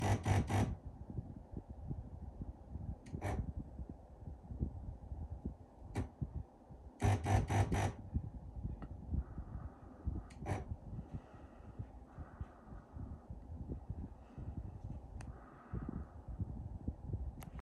Depuis que j’ai activé le mode chauffage depuis quelques semaines, la pompe de relevage sur l’une des unités, fait un bruit bizarre et inhabituel périodiquement.
Bruit pompe de relevage
Votre bruit fait penser au bruit de mise sous tension de la pompe.